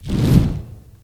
spawners_mobs_balrog_fireball.ogg